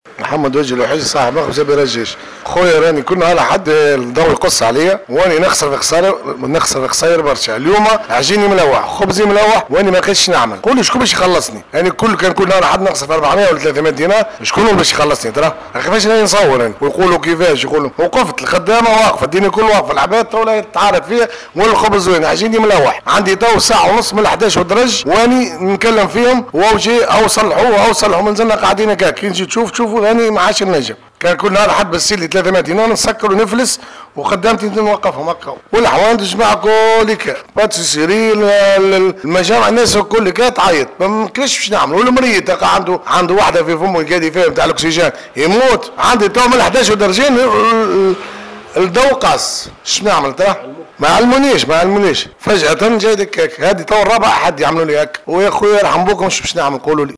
خباز يتحدث للجوهرة أف أم